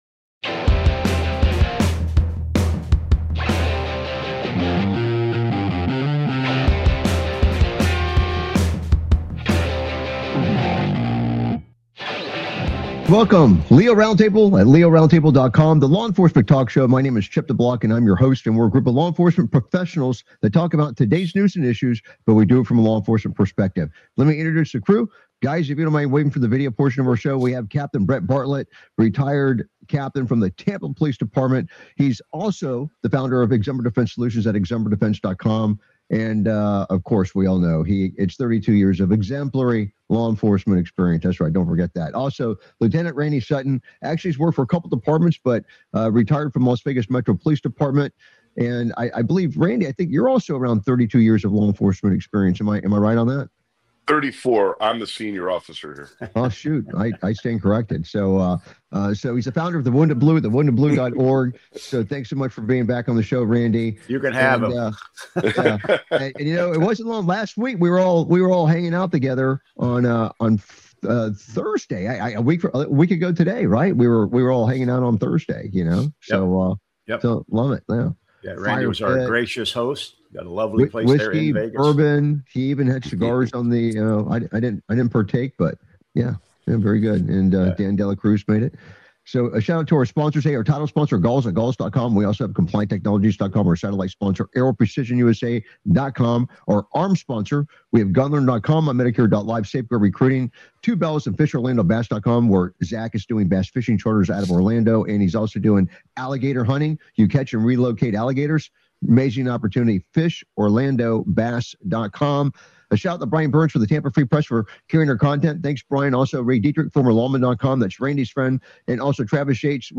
Talk Show Episode, Audio Podcast, LEO Round Table and S11E021, Wild Lady Holding Man Hostage Thought The Police Would Cooperate With Her on , show guests , about Wild Lady Holding Man Hostage,S11E021 Wild Lady Holding Man Hostage Thought The Police Would Cooperate With Her, categorized as Entertainment,Military,News,Politics & Government,National,World,Society and Culture,Technology,Theory & Conspiracy